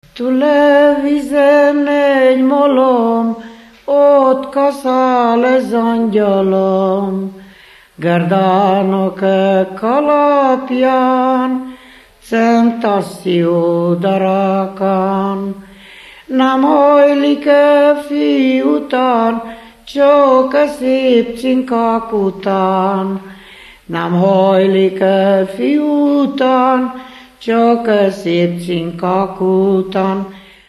Moldva és Bukovina - Moldva - Trunk
ének
Stílus: 7. Régies kisambitusú dallamok
Kadencia: b3 (1) 4 1